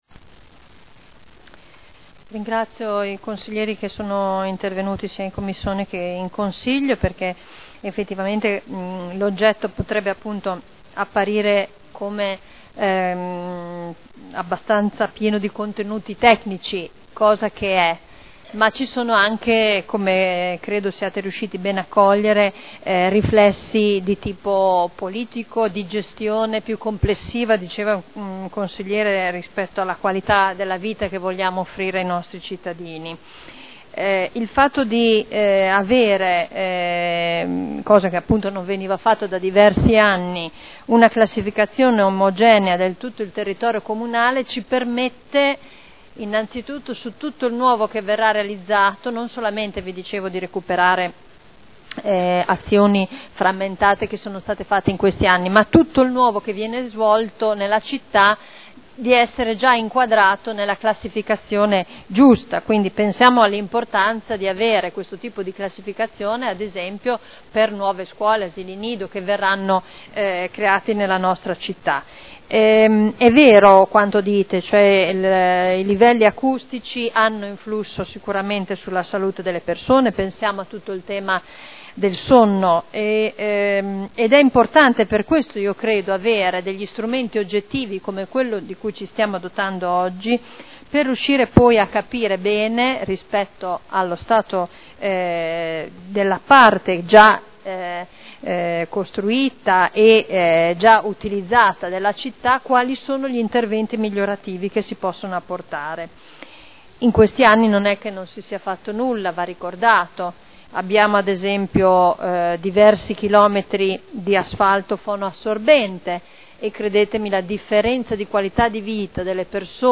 Simona Arletti — Sito Audio Consiglio Comunale
Seduta del 24 febbraio. Proposta di deliberazione: Aggiornamento della classificazione acustica del territorio comunale – Adozione.